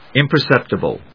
アクセント音節ìm・per・cèp・ti・bíl・ity 発音記号読み方/‐sèptəbíləṭi/ 名詞